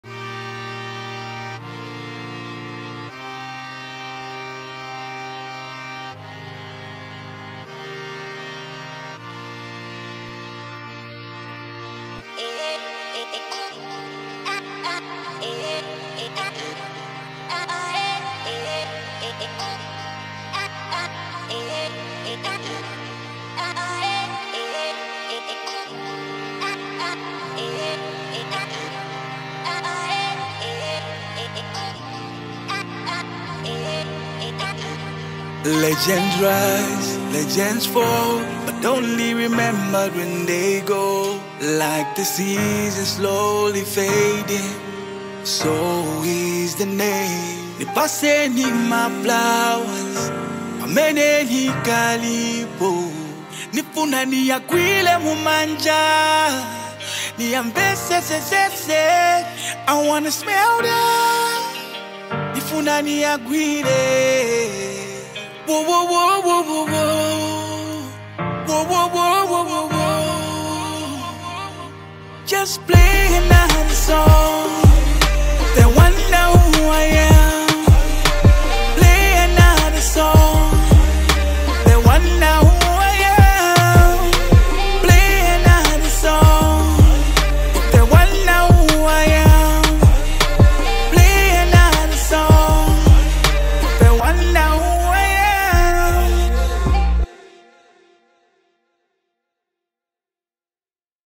With his signature R&B and Afro-soul blend